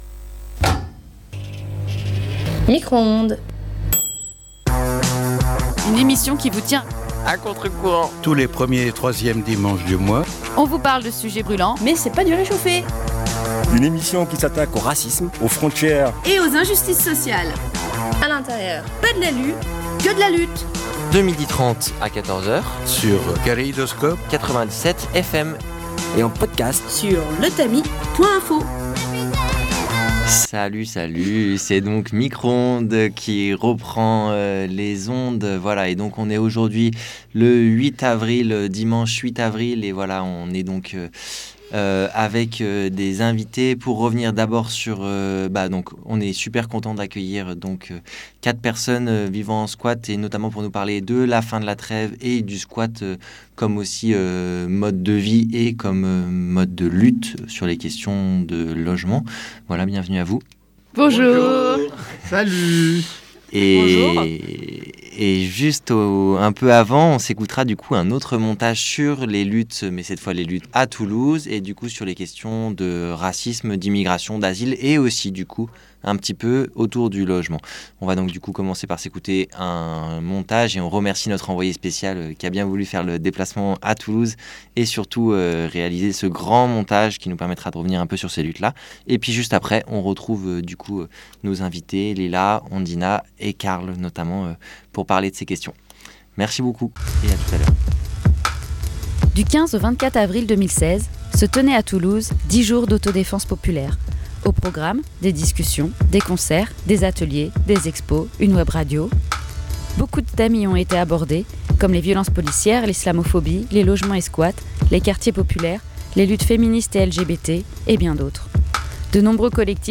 Micro-Ondes est une émission de radio diffusée tous les premiers dimanches du mois de 12h30 à 14h, sur Radio Kaléidoscope (97FM).